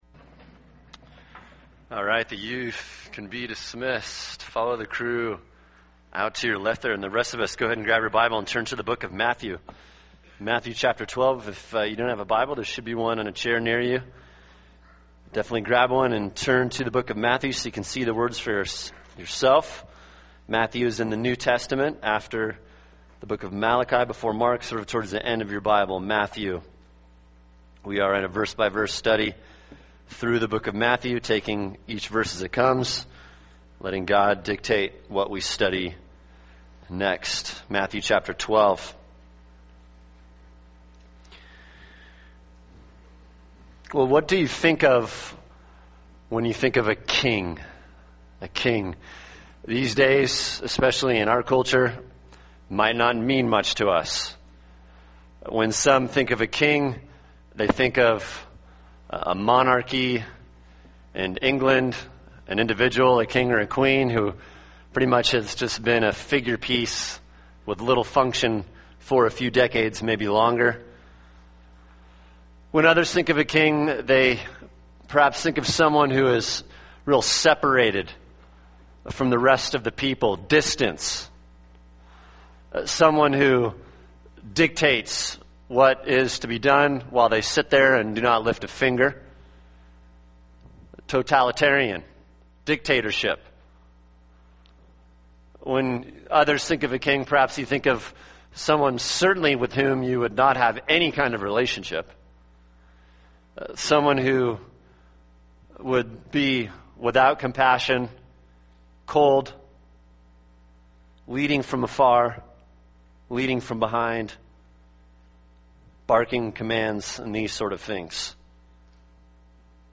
[sermon] Matthew 12:9-21 – No King Like Christ | Cornerstone Church - Jackson Hole